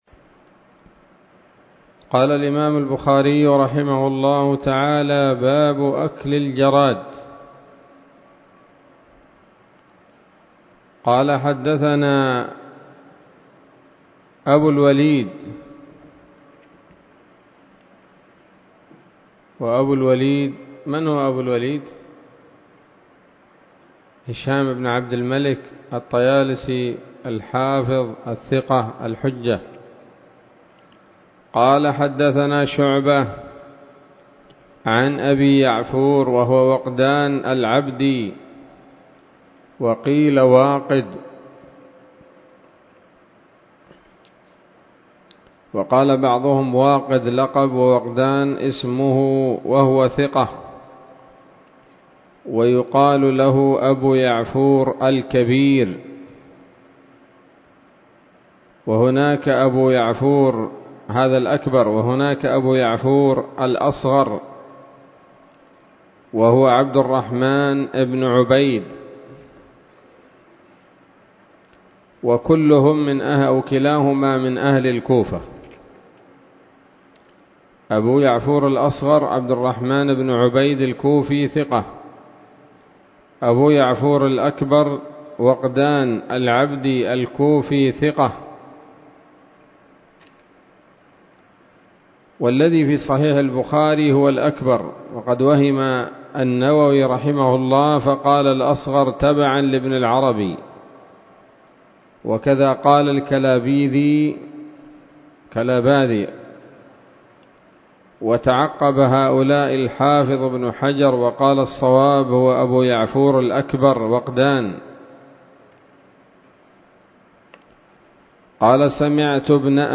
الدرس الحادي عشر من كتاب الذبائح والصيد من صحيح الإمام البخاري